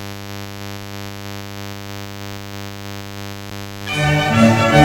Continuous Background Hum - EZCAP cassette
The main interference happens at 100Hz, 200Hz, etc. and up.
But, that looks very bad, and it doesn’t look like AC hum (since it’s squared-off)… But, I’d still say it is a defect in the cassette player.